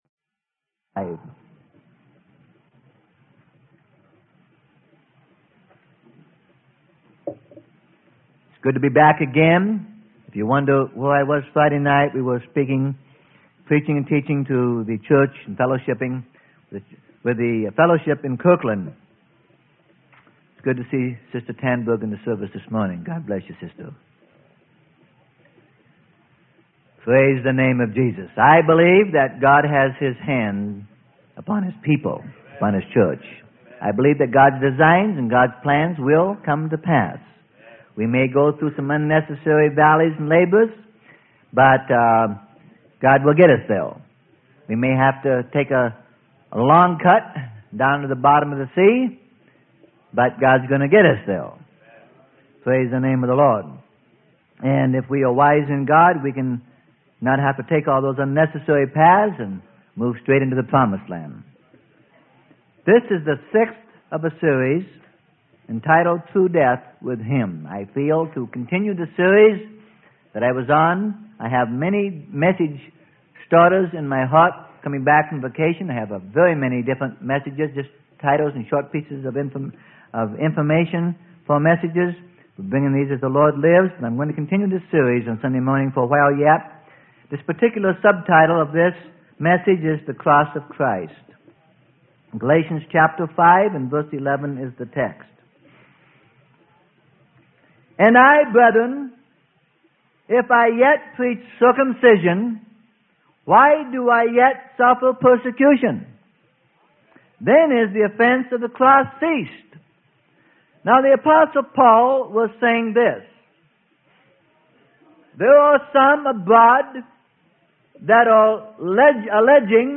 Sermon: Through Death with Him - Part 06 - Freely Given Online Library